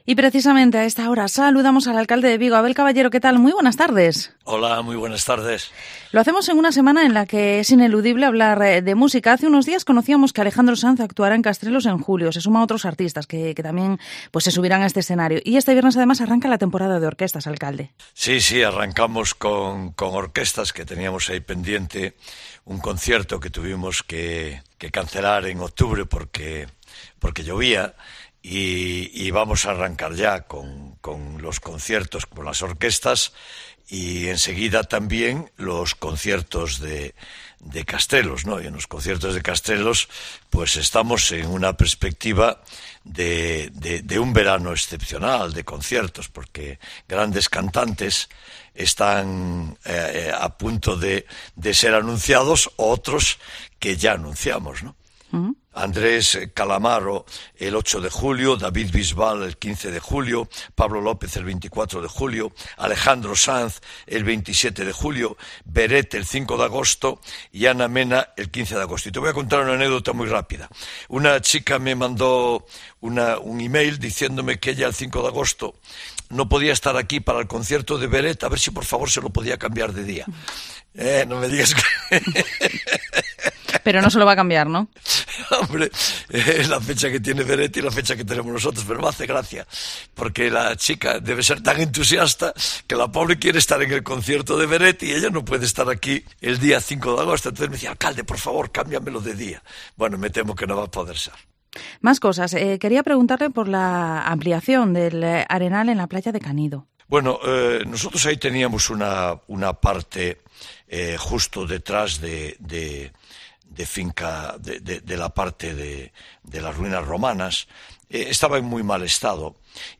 Entrevista al Alcalde de Vigo, Abel Caballero